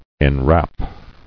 [en·wrap]